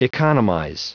Prononciation du mot economize en anglais (fichier audio)